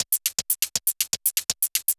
Index of /musicradar/ultimate-hihat-samples/120bpm
UHH_ElectroHatB_120-04.wav